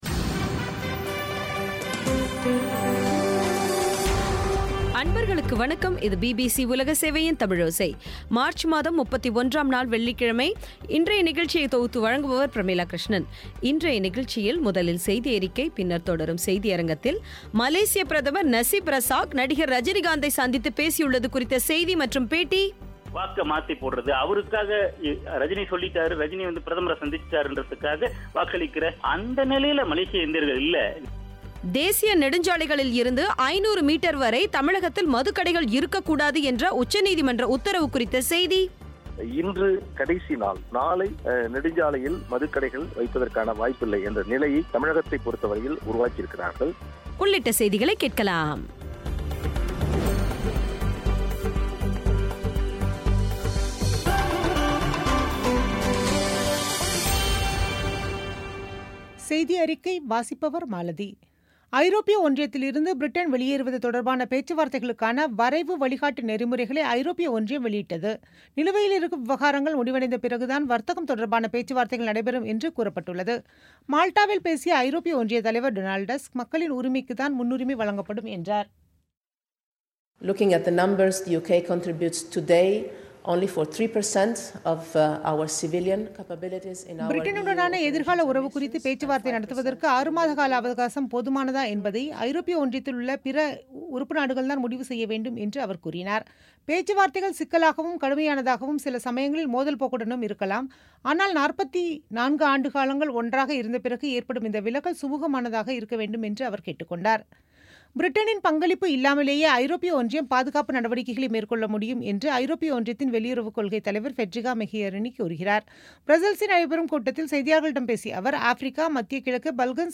மலேசியப் பிரதமர் நஸீப் ரஸாக், நடிகர் ரஜினிகாந்தை சந்தித்துப் பேசியுள்ளது குறித்த செய்தி மற்றும் பேட்டி